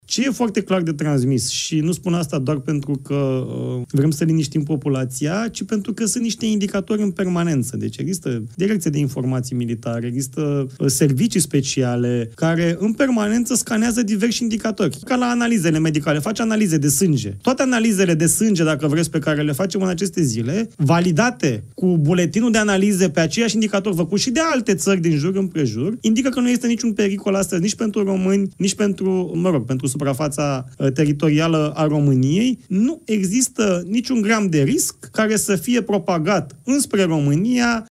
„Nu este ceva ieșit din comun”, a declarat ministrul Apărării la Adevărul Live.